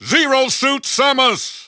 The announcer saying Zero Suit Samus' name in English and Japanese releases of Super Smash Bros. Brawl.
Zero_Suit_Samus_English_Announcer_SSBB.wav